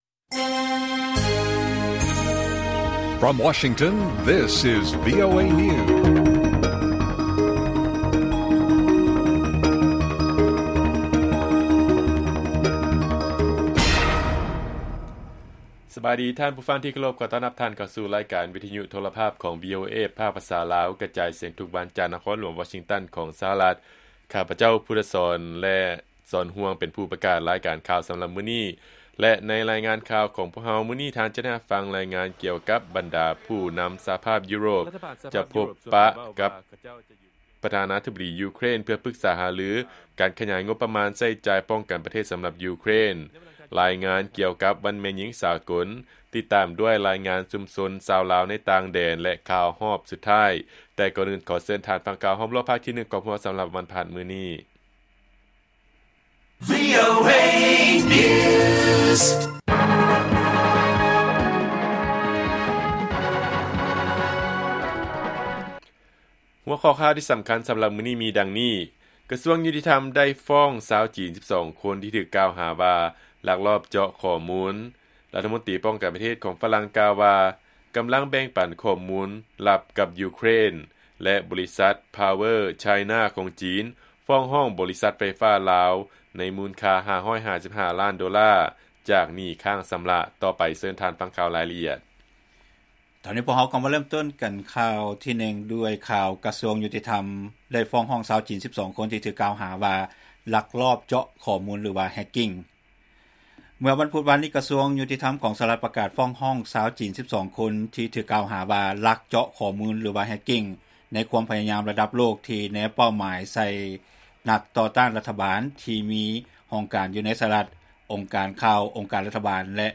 ລາຍການກະຈາຍສຽງຂອງວີໂອເອ ລາວ : ກະຊວງຍຸຕິທຳໄດ້ຟ້ອງຊາວຈີນ 12 ຄົນທີ່ຖືກກ່າວຫາວ່າ ລັກລອບເຈາະຂໍ້ມູນ ຫຼື hacking